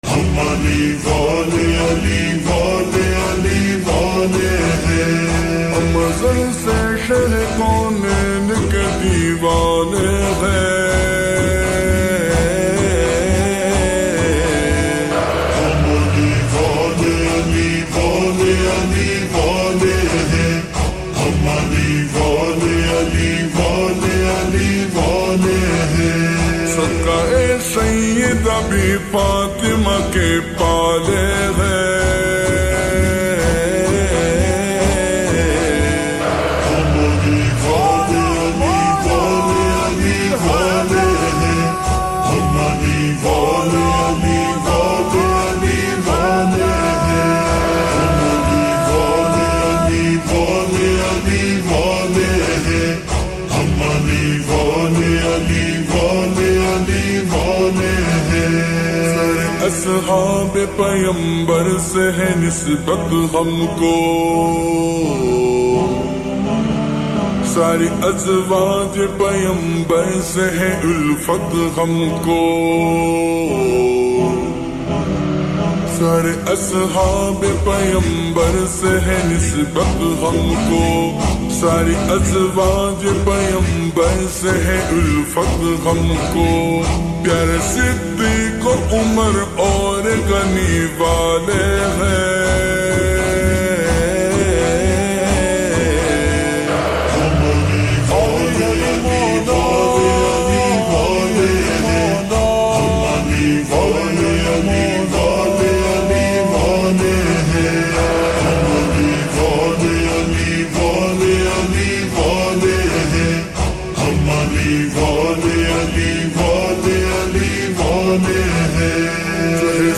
Naat Slowed Reverb